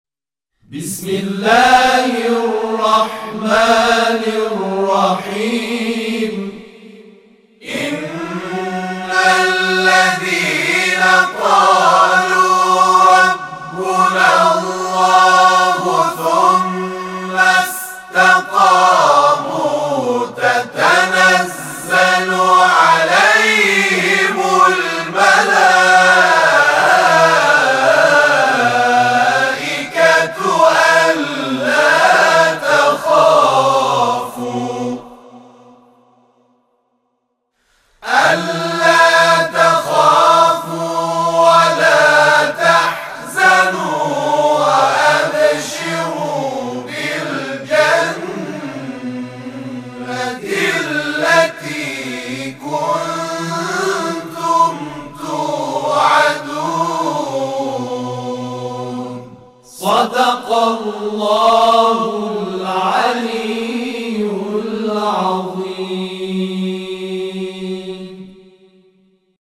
Pembacaan Kolektif Surah Al-Fussilat Ayat 30 oleh Kelompok Tawasih Muhammad Rasulullah (saw)
Sehubungan dengan hal tersebut, telah diterbitkan bacaan kolektif surah Al-Fussilat ayat 30.